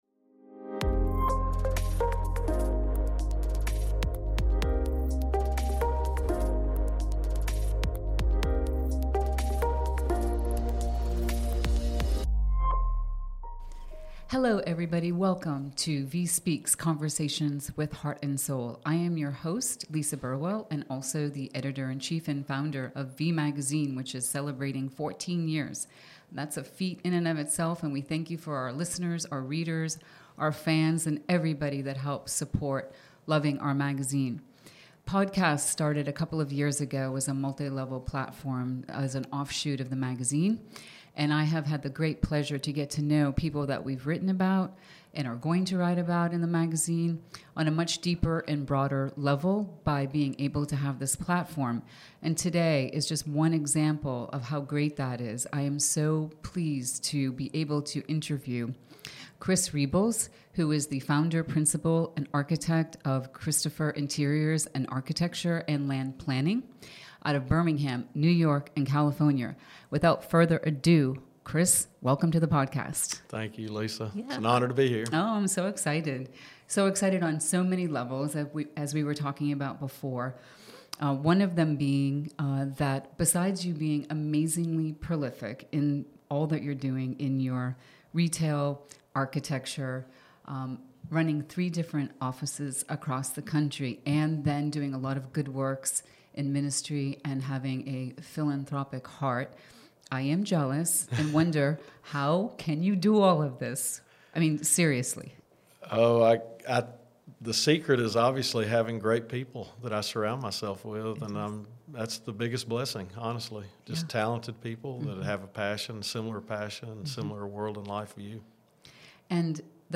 Tune in for a conversation grounded in grace, service, and passion for creating intentional architecture by keeping the customer vision at the forefront of every project.